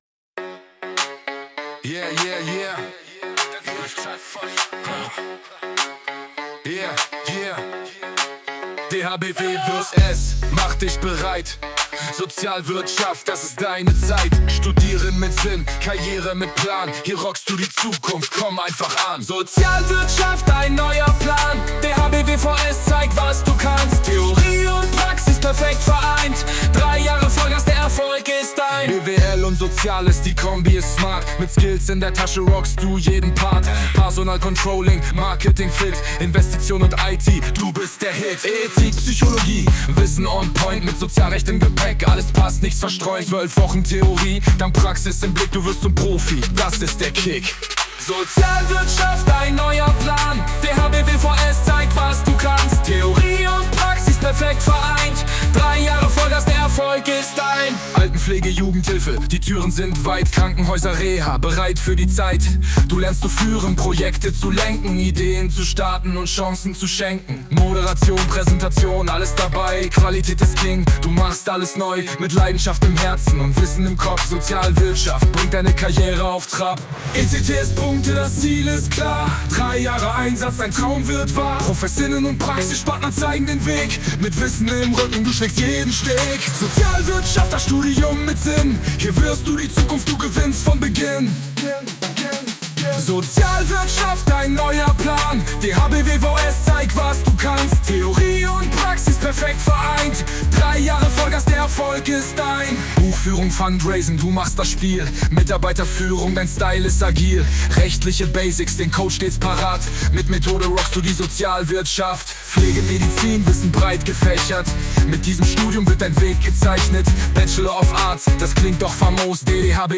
Mit Hilfe von KI belebte er seinen Text zur "Sozialwirtschaft".
Song: